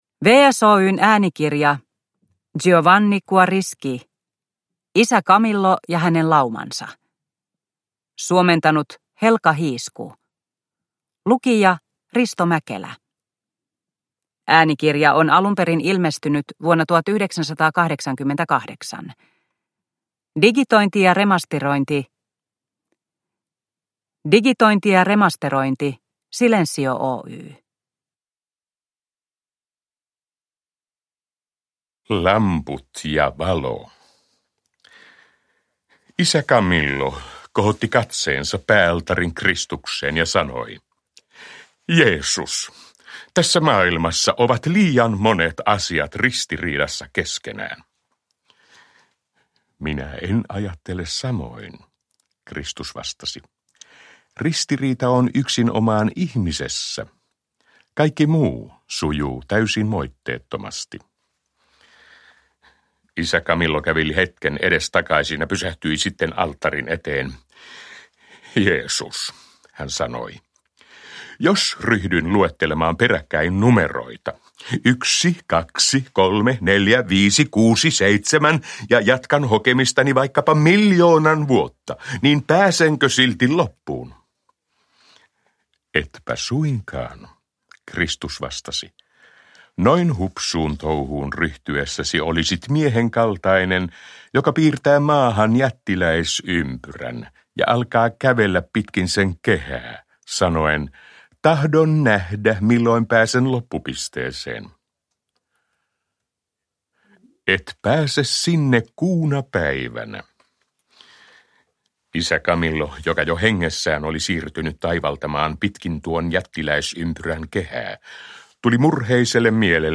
Isä Camillo ja hänen laumansa – Ljudbok – Laddas ner